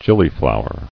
[gil·ly·flow·er]